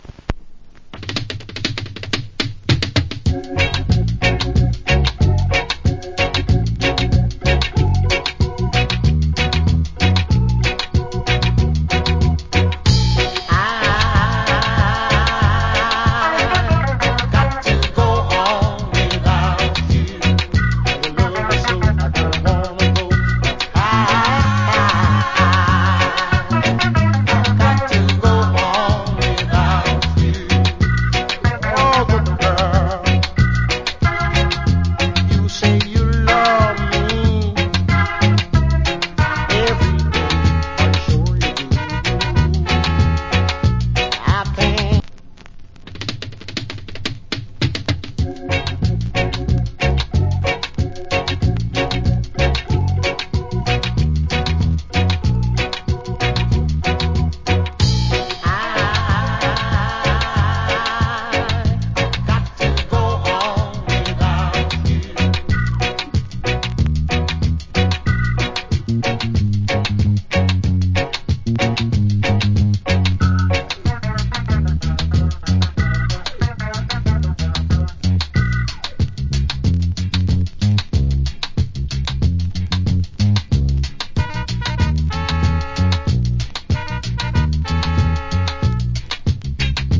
Cool Reggae Vocal.